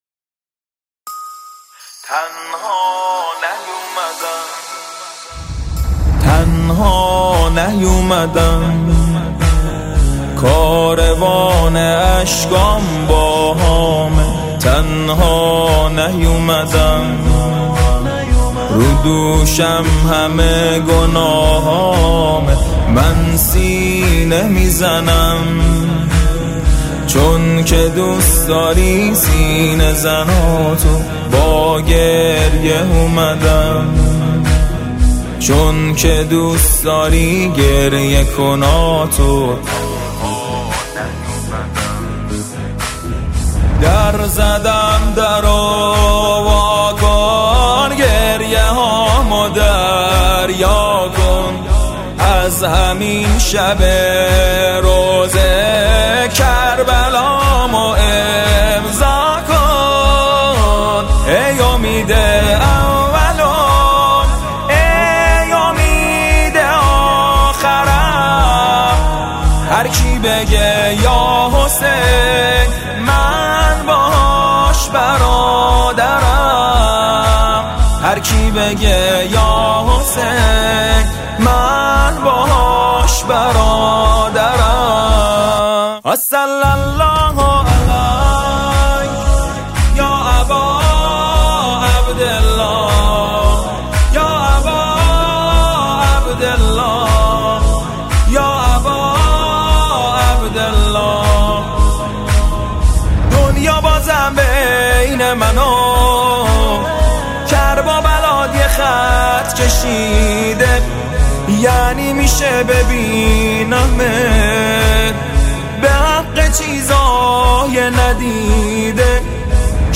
دلنشین
مداحی